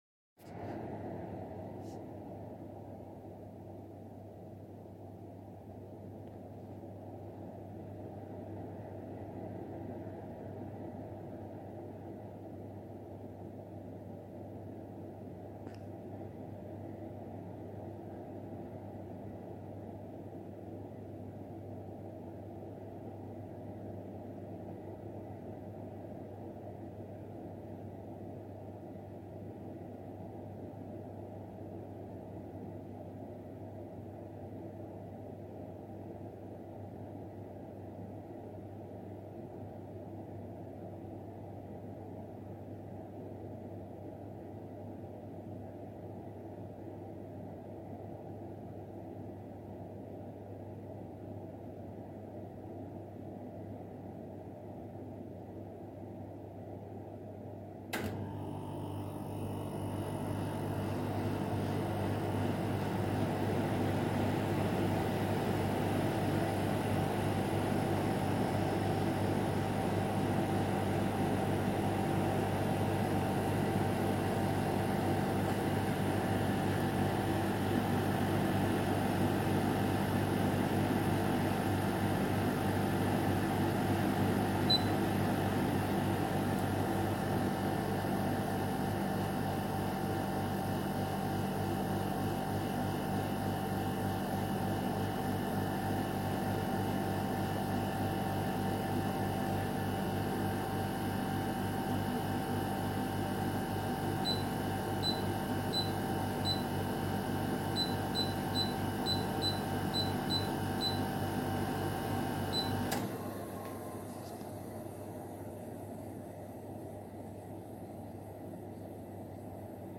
Question forum dépannage climatisation : Bruit compreseur climatiseur mobile Dolceclima silent 10
Les données sont bonnes il y a comme un bruit de claquement désagréable comme un vieux frigo...
Bonjour voici les fichiers demandées, début air au minimum puis déclenchement du compresseur puis retour normal..
Le bruit qui me dérange c'est bruit de claquement continu qui est omniprésent.